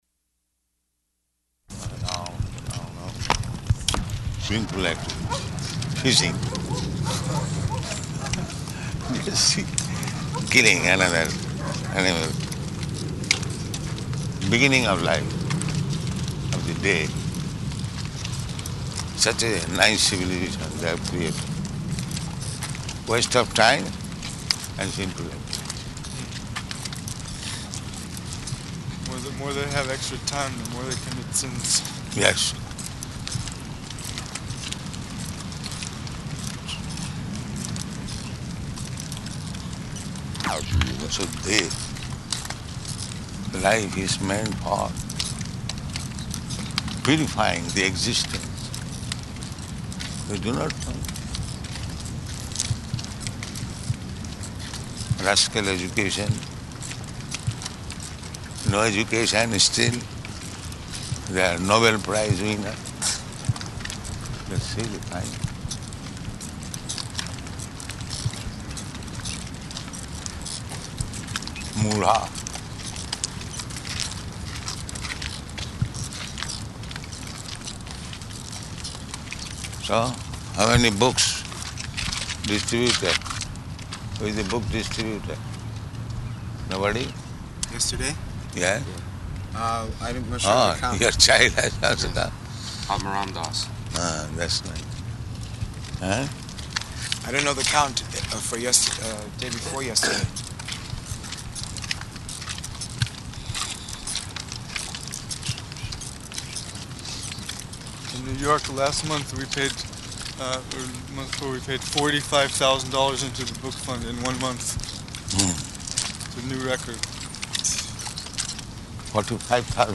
Morning Walk, partially recorded
Type: Walk
Location: Honolulu